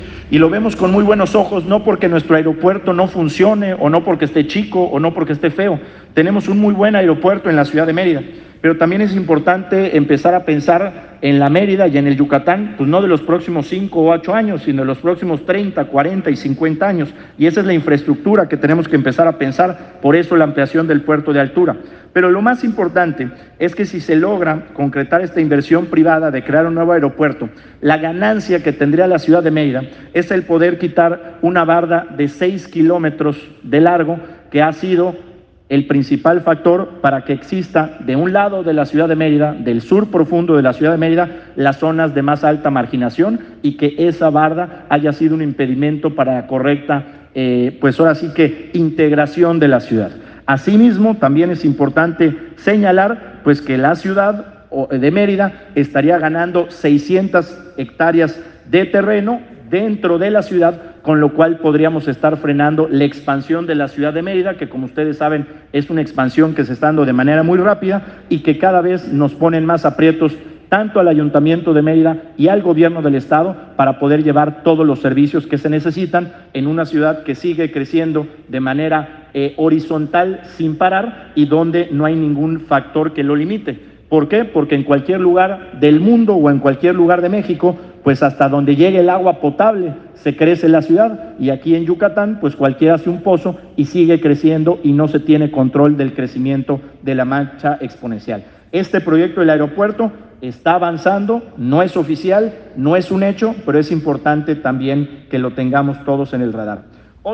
El jefe del Ejecutivo habló hoy de la que podría ser la nueva terminal aérea de Mérida durante la toma de compromiso de la directiva de la delegación Mérida de la Cámara Nacional de Comercio, Servicios y Turismo (Canacome).
Estas fueron las declaraciones de Mauricio Vila sobre el plan del nuevo aeropuerto: